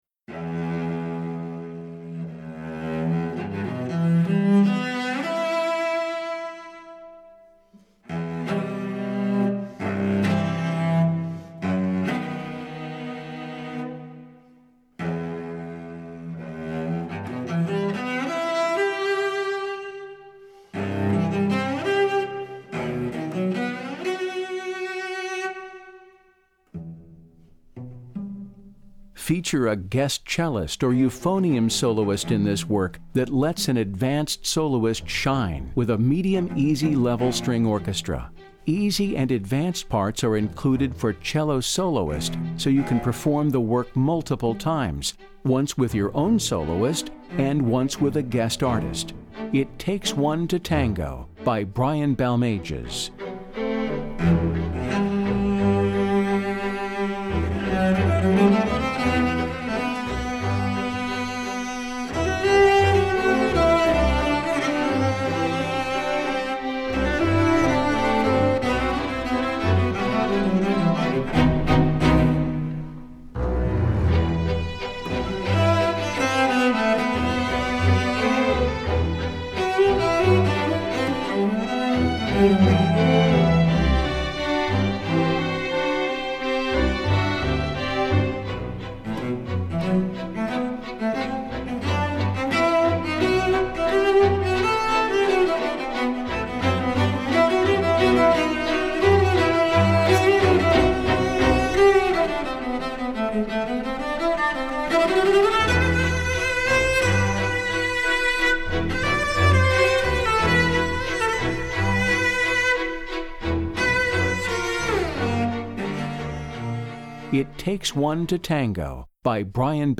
Voicing: Cello/Orch